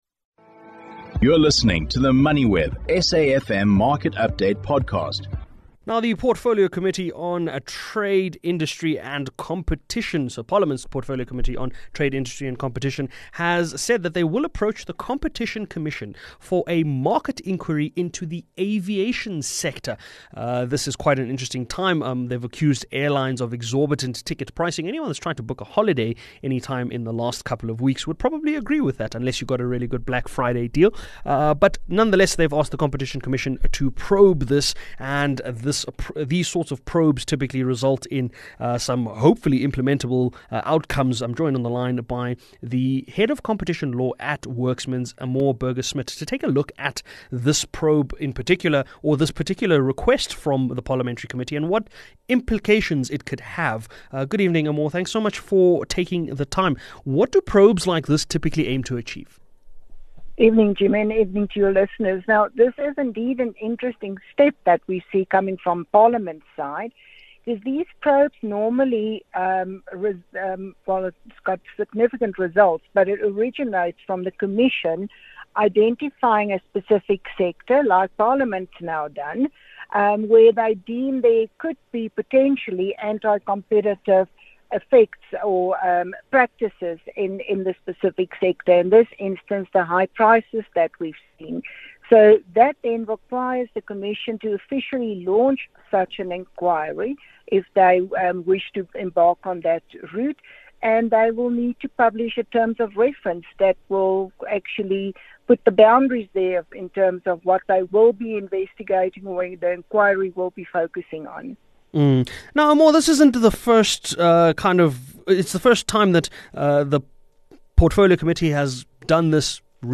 … continue reading 3299 episodi # Business # South Africa Economics # South Africa # Moneyweb Radio # News